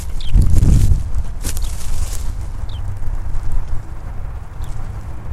Kurzzehenlerche Calandrella brachydactyla Greater Short-toed Lark
Rosenheim-Pang RO, 29.09.2011 Rufe 6 s